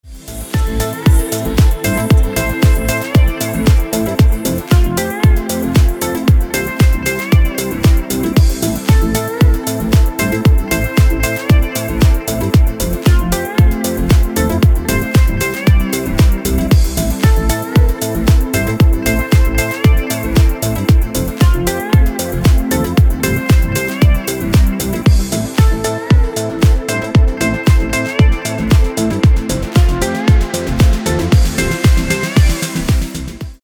красивые
dance
спокойные
без слов